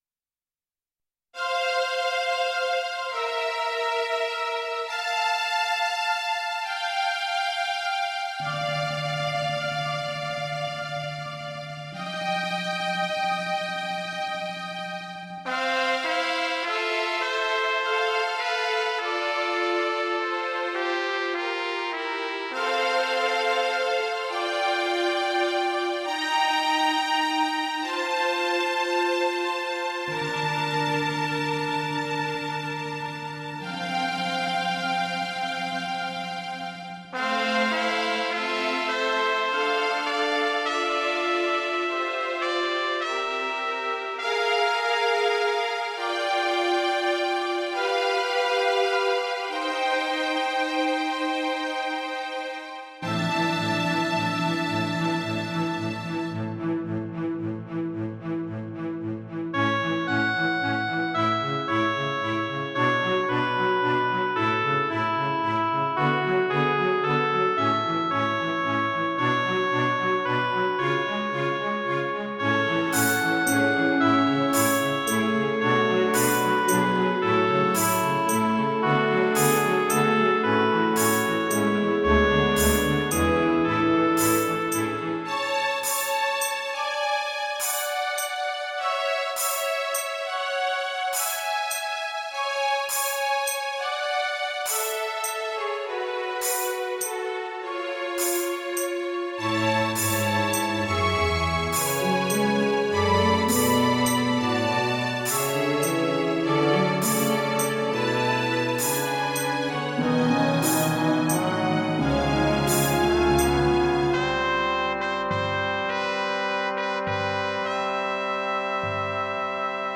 クラシック 　ＭＩＤＩ(82KB） 　YouTube